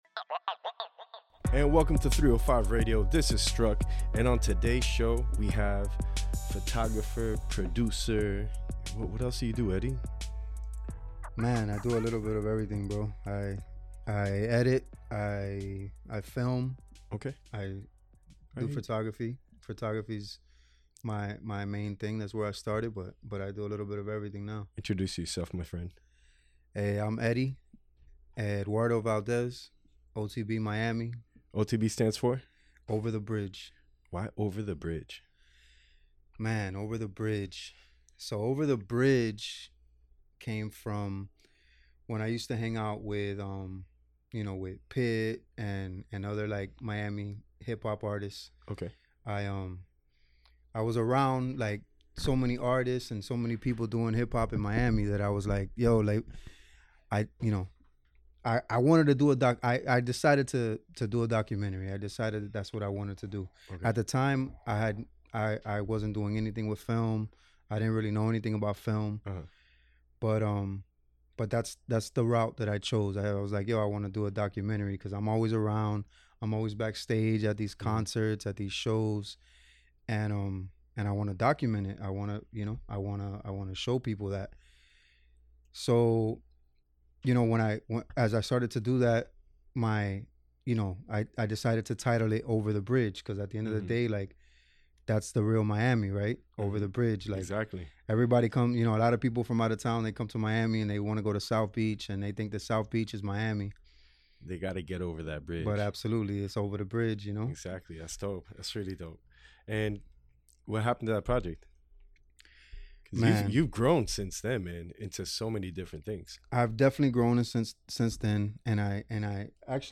Show instrumental